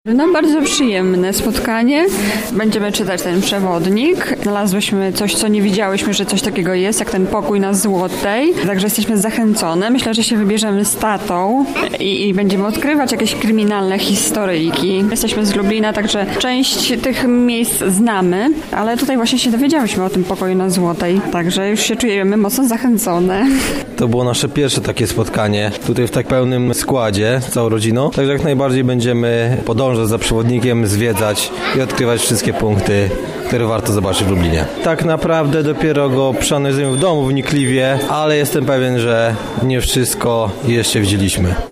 Z uczestnikami spotkania rozmawiała nasza reporterka.